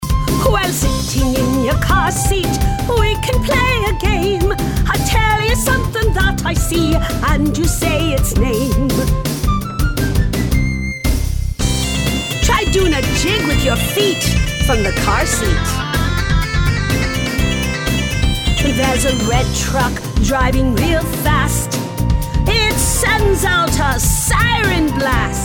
Love the Celtic sound!